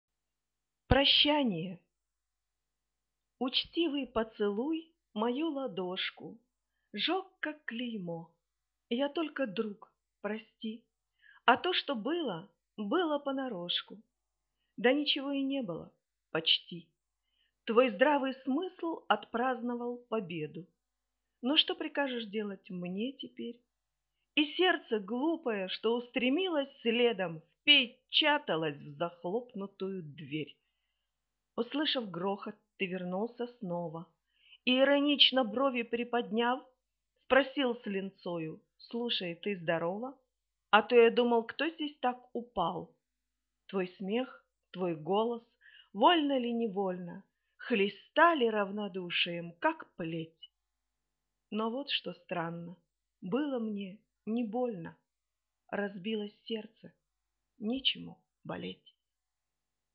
Прощание (вслух)
Да, читаю я. Но друзья говорят, что в жизини тембр немного пониже, запись искажает.